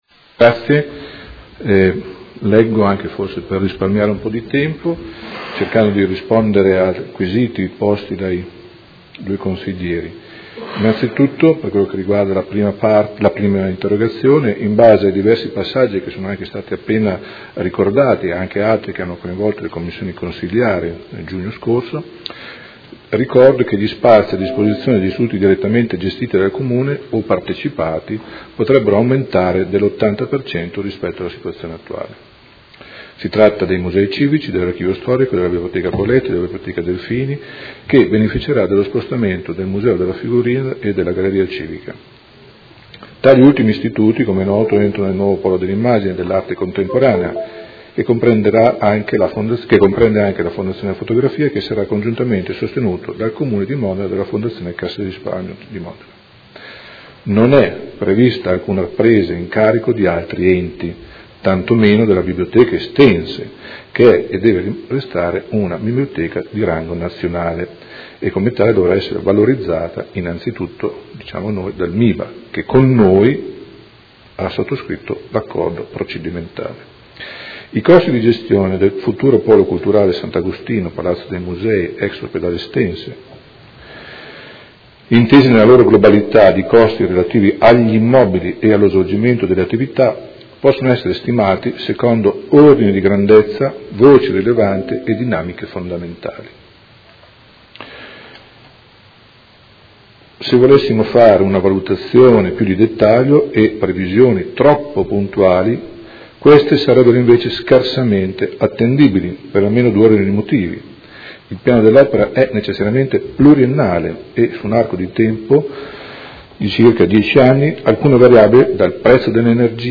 Seduta del 24 novembre 2016. Riponde a Interrogazione del Consigliere Montanini (CambiAMOdena) avente per oggetto: Gestione nuovo Polo Sant’Agostino e a Interrogazione del Gruppo Movimento cinque Stelle avente per oggetto: Stato di fatto del progetto del Polo Culturale Sant’Agostino